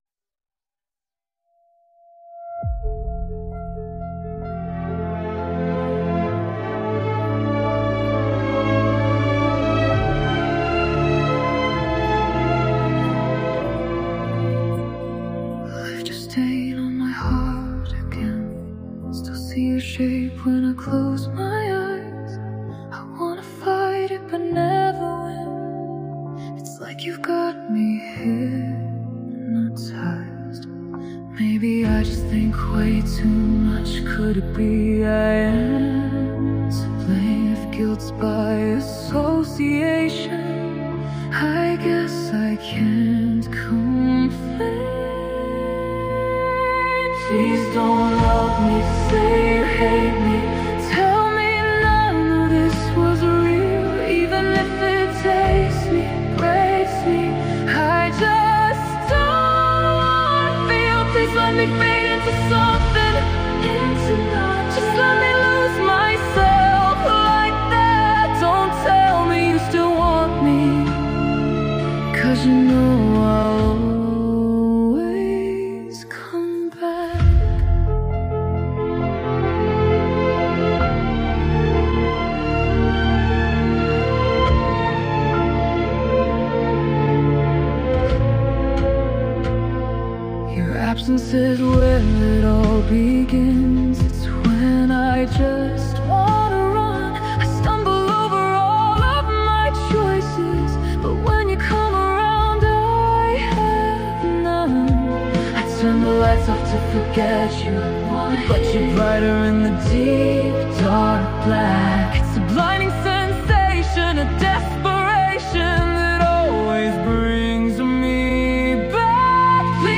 Adult Contemporary